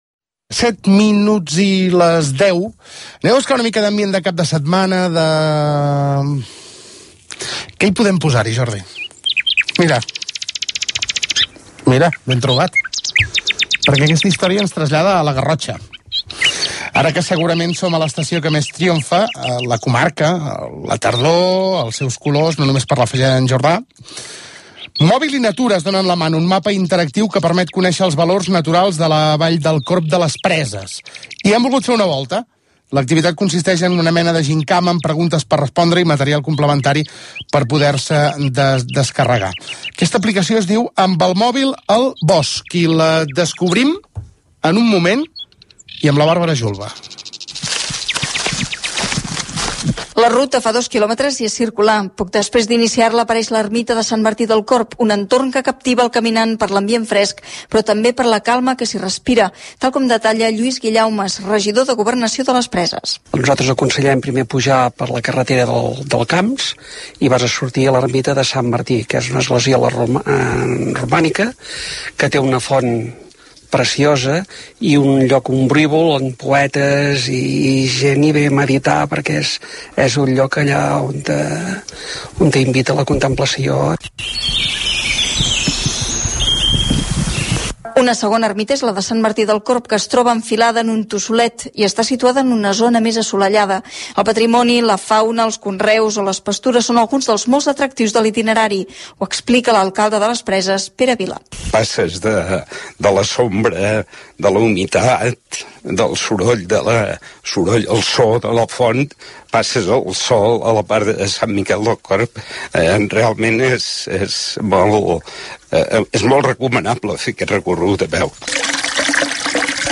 Reportatge ruta des de la Garrotxa - RAC1, 2015